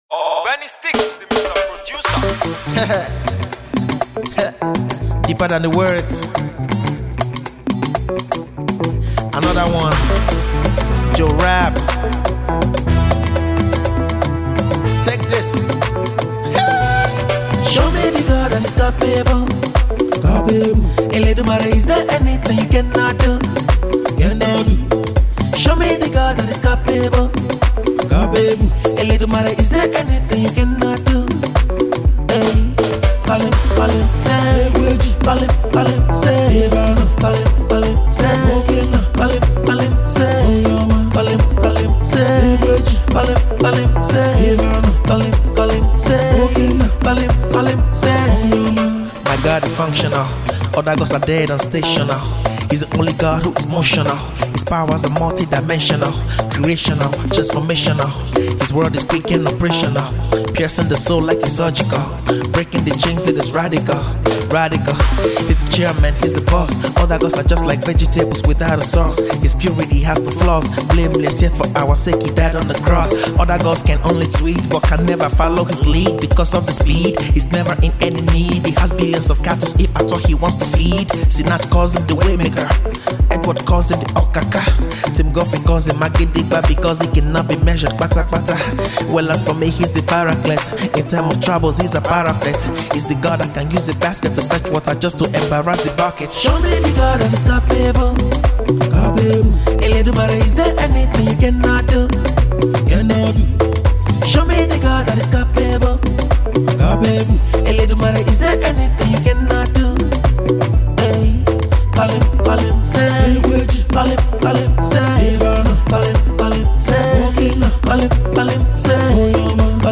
Gospel Rapper,Singer and Songwritter
praise song